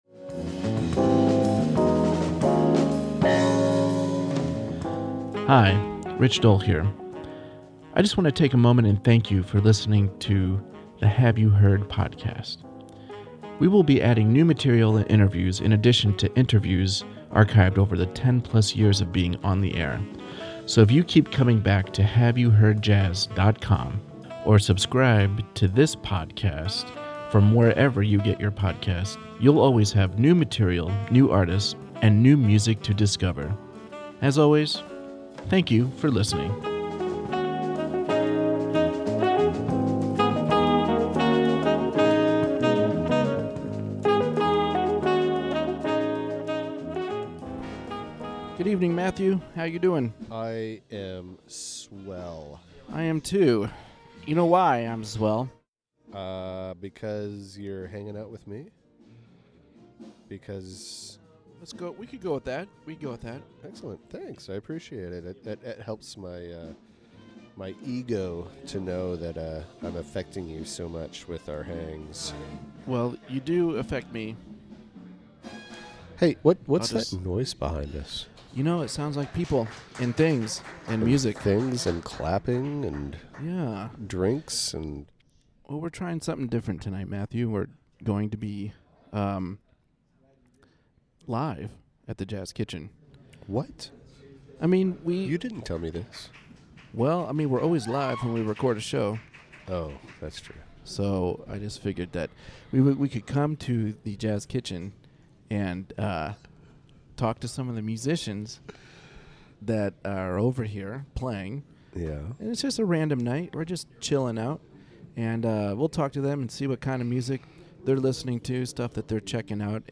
Actually, it was quite easy. We met at The Jazz Kitchen, hooked up our microphones and hit record. We were there during the weekly Jazz Jam session and we were able to speak with a few of the musicians who were playing and hanging out.
This band plays the classical music of India.